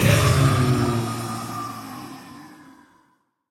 mob / blaze / death.ogg
death.ogg